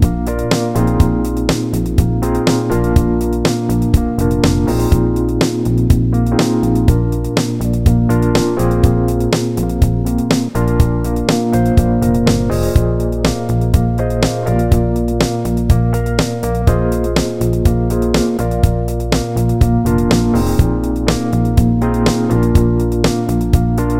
Minus Guitars Pop (1970s) 3:31 Buy £1.50